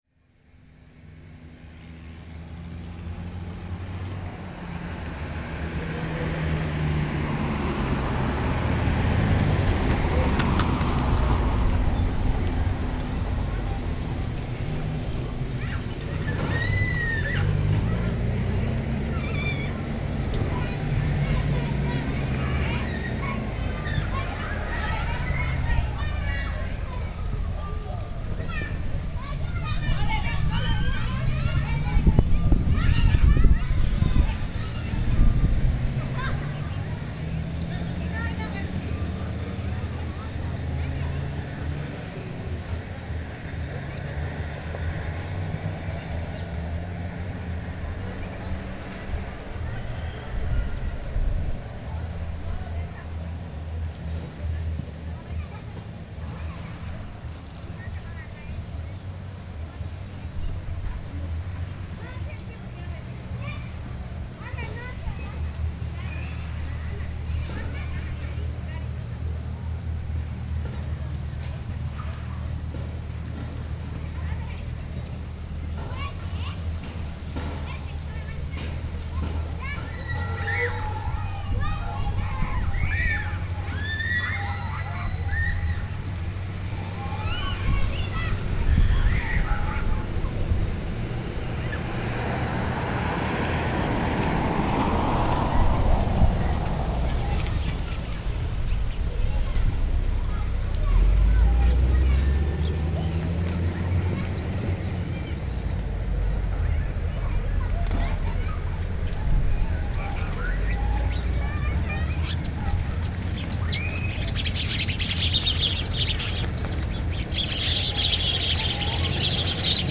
Niños, pájaros, carros
Lugar: Banqueta del Auditorio Municipal de Suchiapa, Chiapas.
Equipo: Grabadora Sony ICD-UX80 Stereo, Micrófono de construcción casera (más info)